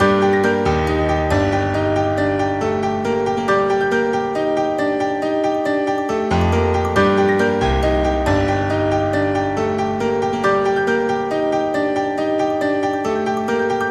Tag: 138 bpm Dubstep Loops Piano Loops 2.34 MB wav Key : G